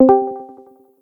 Discord User Joined Sound Effect